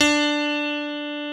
Index of /90_sSampleCDs/Keyboards of The 60's and 70's - CD2/PNO_E.Grand/PNO_E.Grand